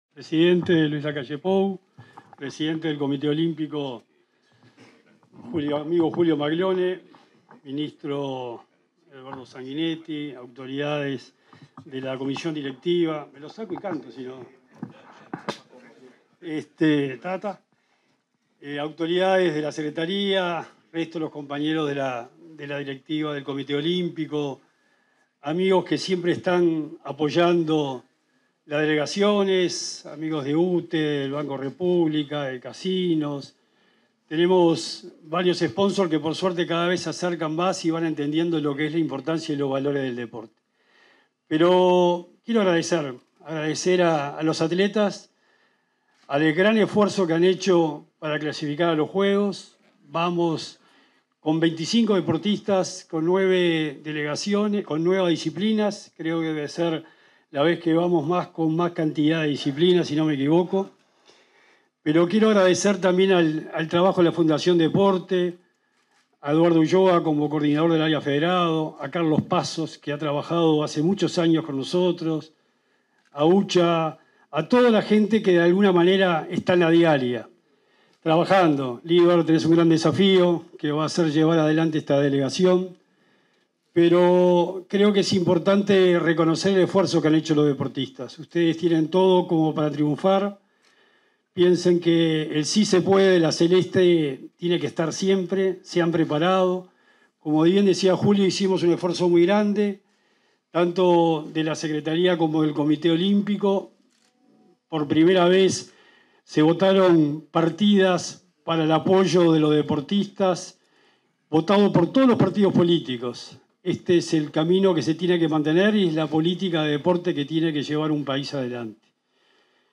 Palabras del secretario nacional del Deporte, Sebastián Bauzá
Palabras del secretario nacional del Deporte, Sebastián Bauzá 10/07/2024 Compartir Facebook X Copiar enlace WhatsApp LinkedIn En el marco de la ceremonia de despedida a deportistas que participarán en los Juegos Olímpicos en París 2024, este 9 de julio, se expresó el secretario nacional del Deporte, Sebastián Bauzá.